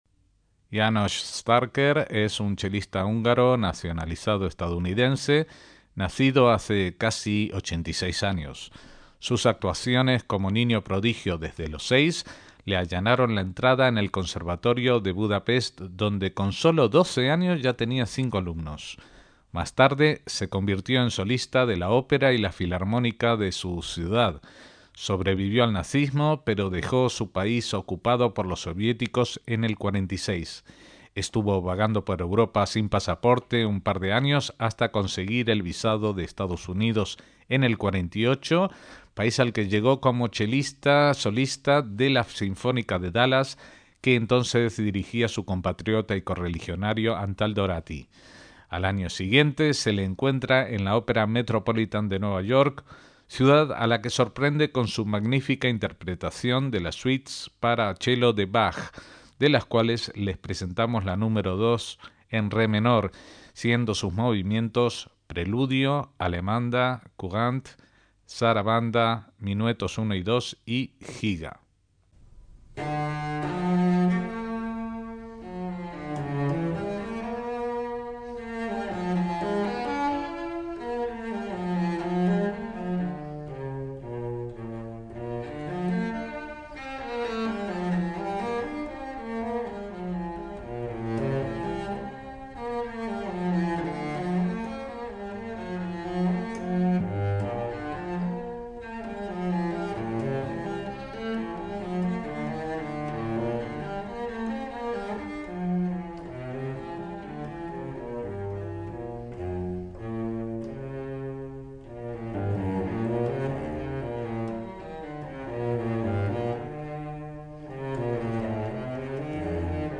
Suite para chelo Nº2 de Bach por Janos Starker
MÚSICA CLÁSICA - János Starker fue un chelista